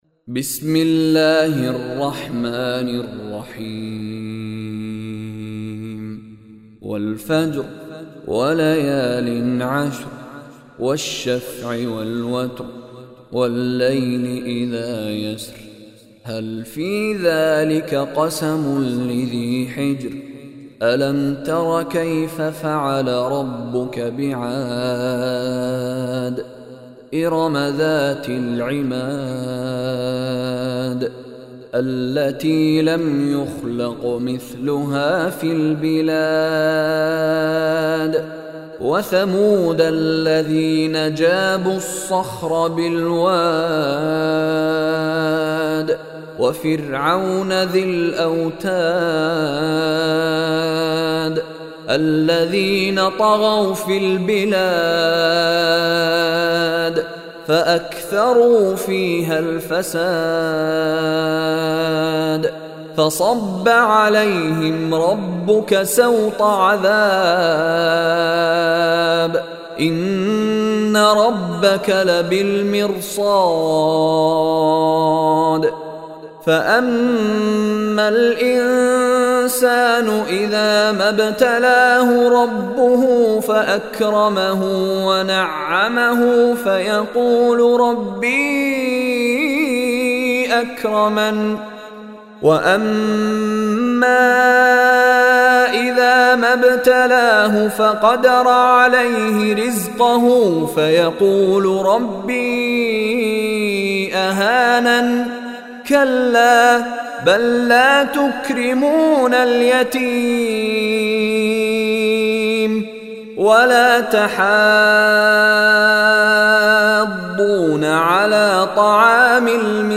Surah Al Fajr Recitation by Mishary Rashid
Surah Al-Fajr listen online mp3, recited in Arabic and download mp3 audio in the voice of Sheikh Mishary Rashid Alafasy.